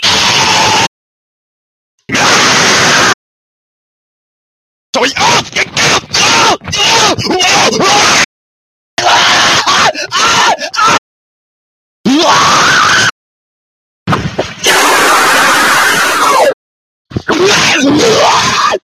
ROBLOX KEEPS REJECTING THE DEATH SCREAM AUDIOS I GOT FROM SOYTEENS THAT I PROMISED TO ADD.